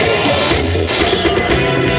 120BPMRAD5-L.wav